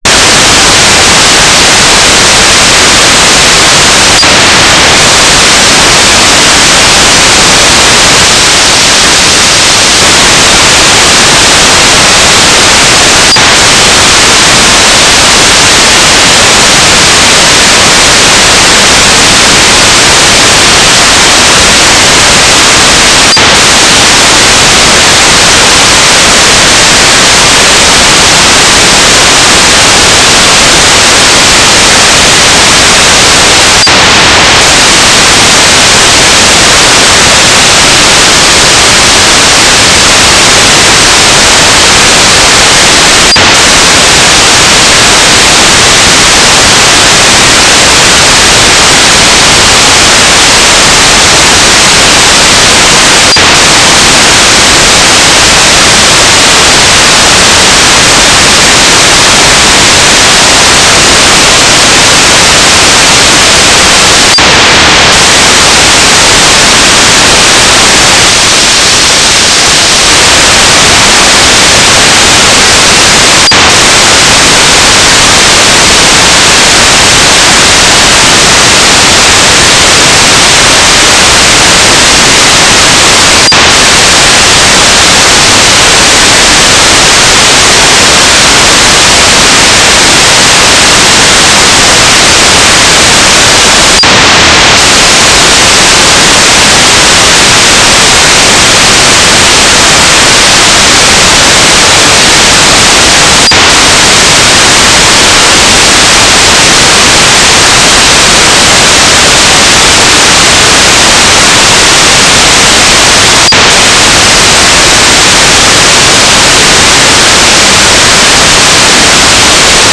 "transmitter_mode": "FSK AX.100 Mode 5",